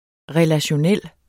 Udtale [ ʁεlaɕoˈnεlˀ ]